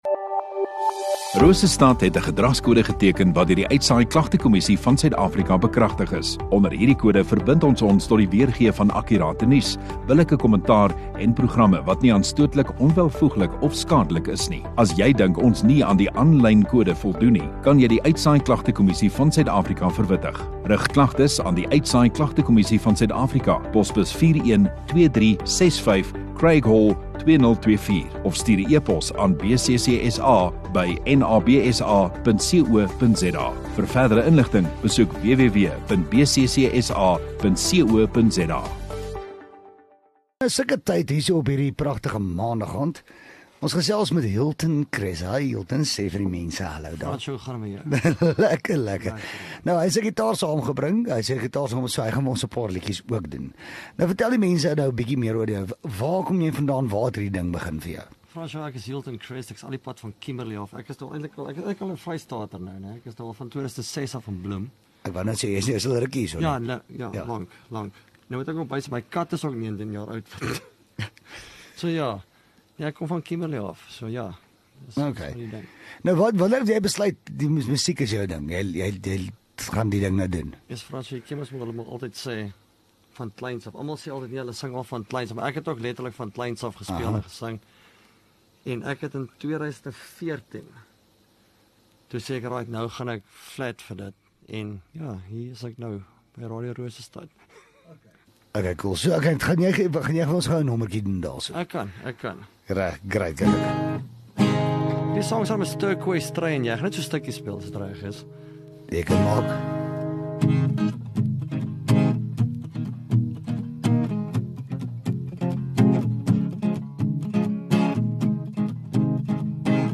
Radio Rosestad View Promo Continue Radio Rosestad Installeer Kunstenaar Onderhoude 29 Sep Kunstenaar